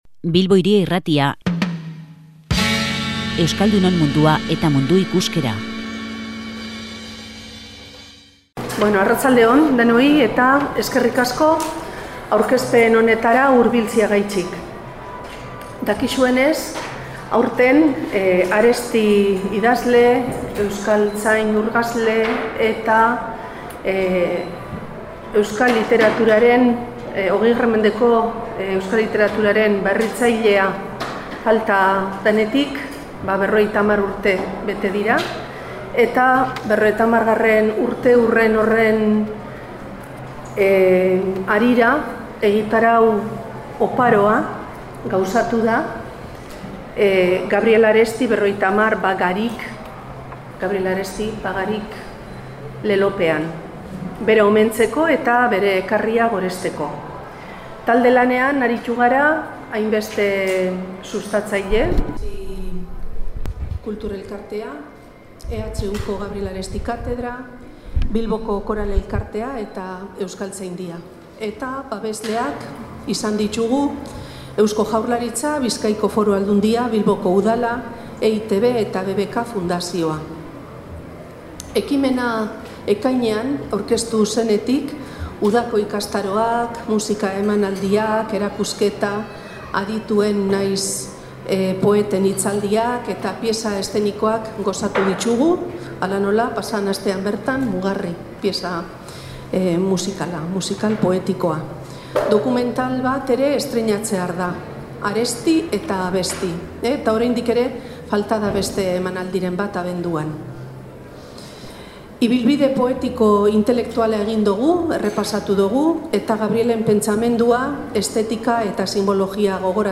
Euskaltzaindiak ‘Gabriel Arestiren Mandatua’ aurkeztu du Durangoko Azokan